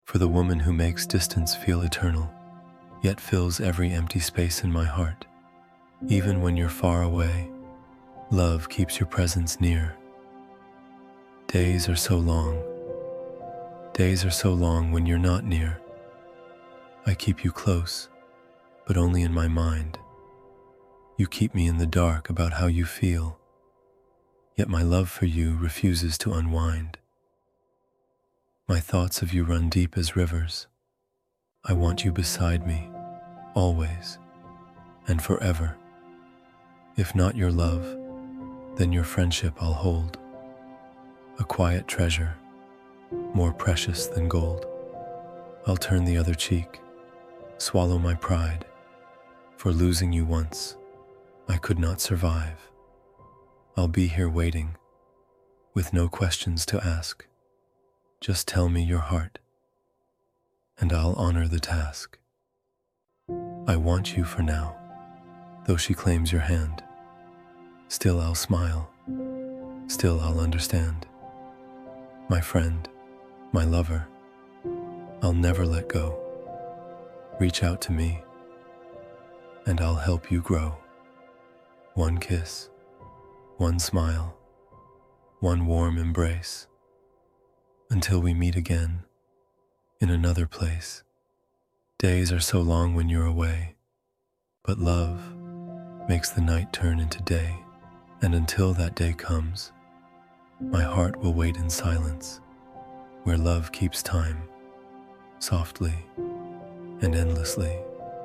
Days-Are-So-Long-–-Love-Poem-Spoken-Word-MP3.mp3